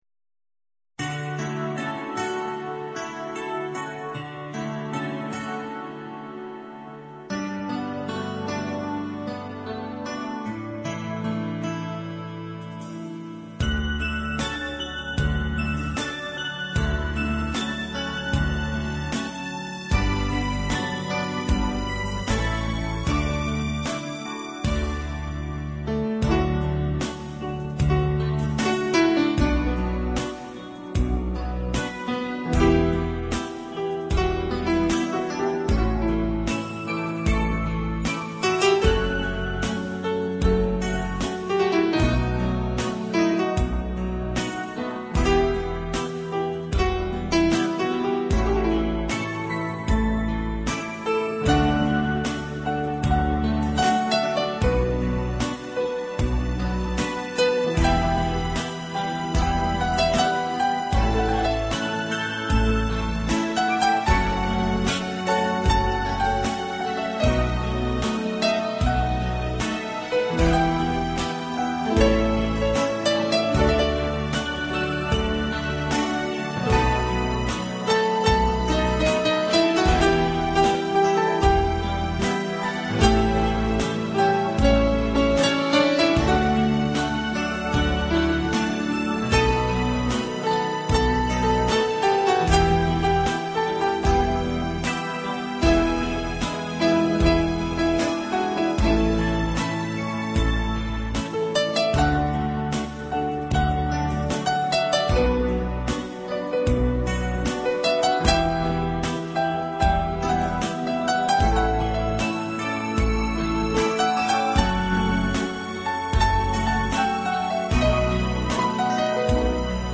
0337-钢琴名曲爱的箴言.mp3